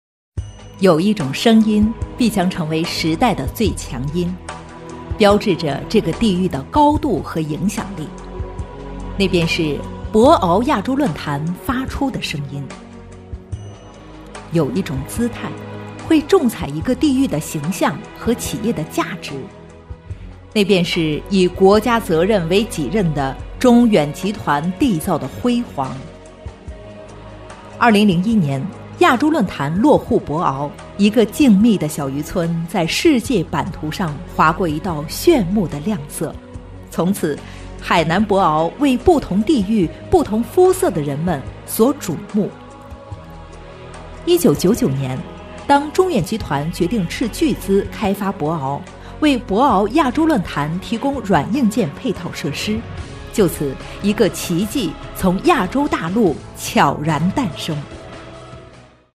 女33特价成熟-纵声配音网
女33 (娓娓道来)博鳌亚洲论坛.mp3